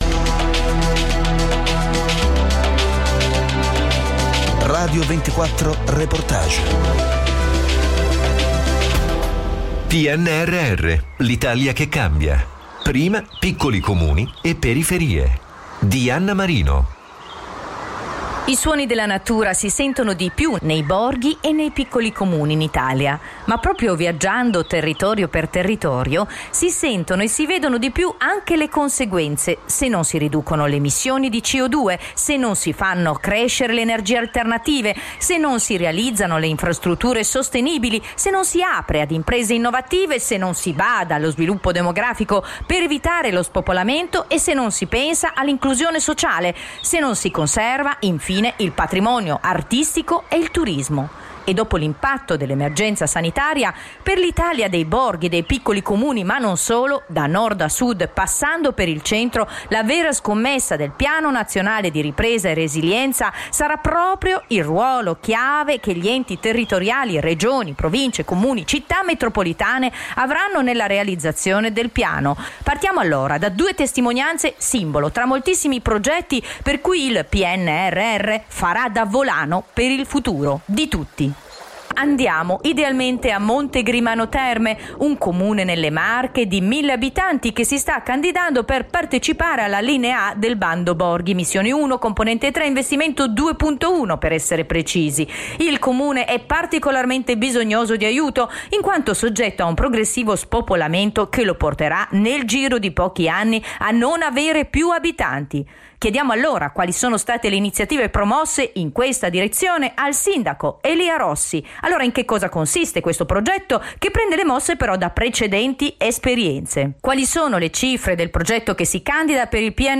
Podcast: The Mayor on Radio24 Talks About PNRR Funds – Monte Grimano Terme